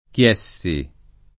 Chiessi [ k L$SS i ]